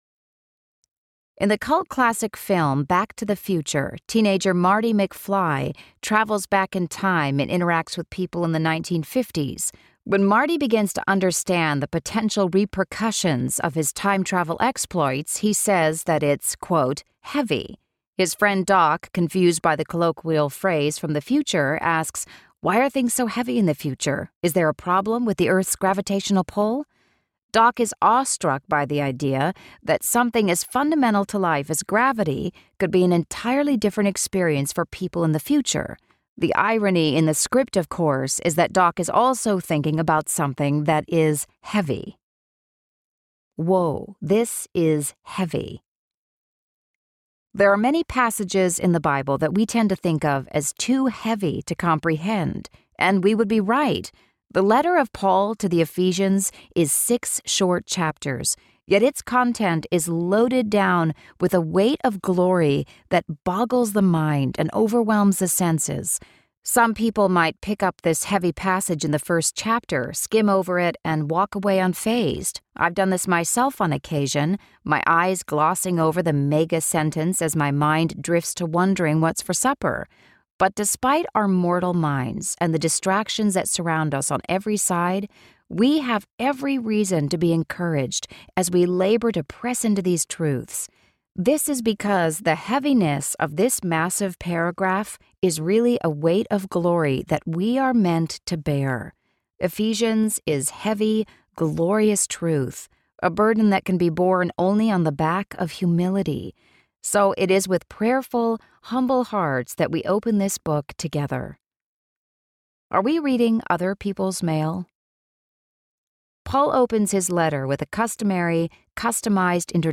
Alive in Him Audiobook
Narrator